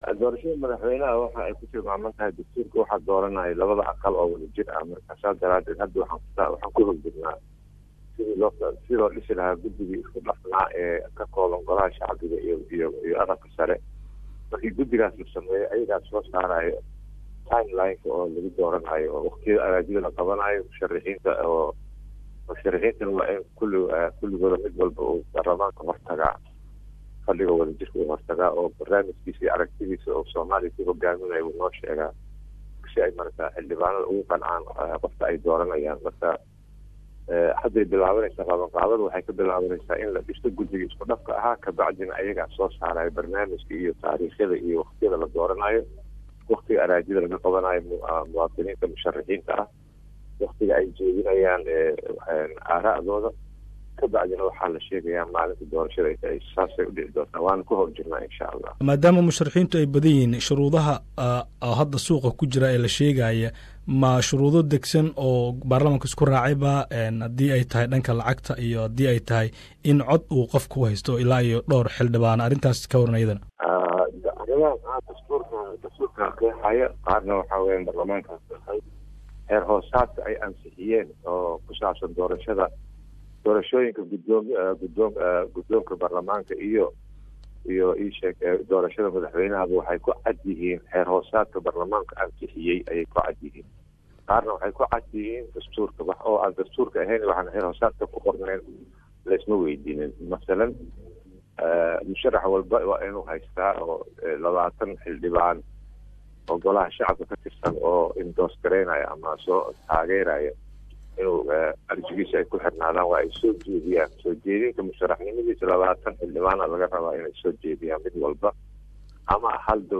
Gudoomiyaha baarlamanka 100aad ee somaliya maxamed sh Cusmna jawaari oo wareysi gaar ah siiyey SBS Somali Ayaa sheegay in gudi ay sameeyeen oo soo diyaarindoono qabsoomida doorashada madaxweyne wareysi dhameytiran halkan ka dhageyso